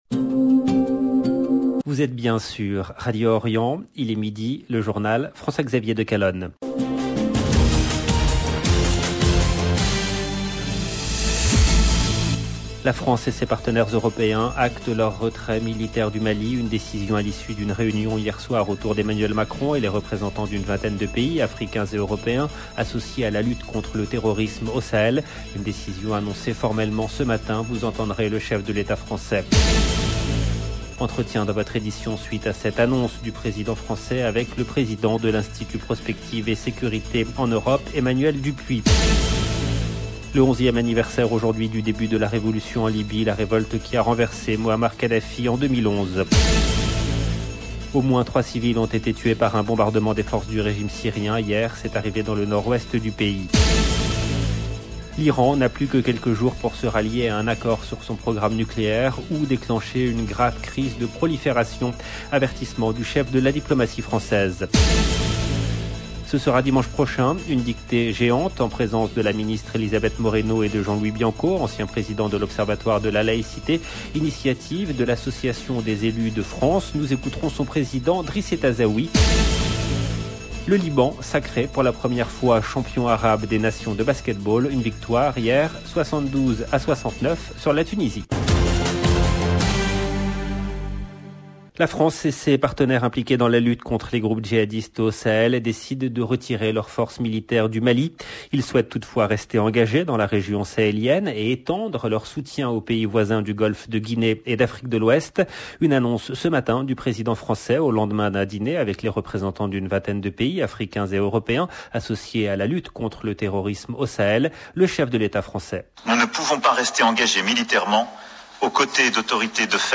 LB JOURNAL EN LANGUE FRANÇAISE
Vous entendrez le chef de l’Etat Français.